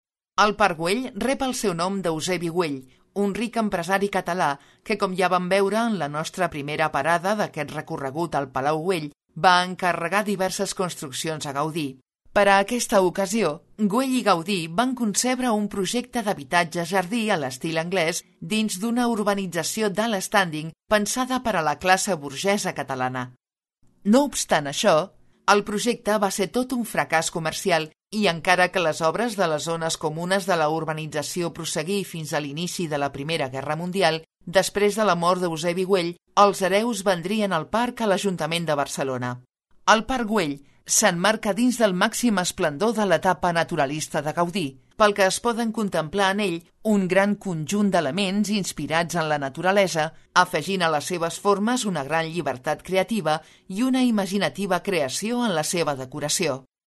Audioguía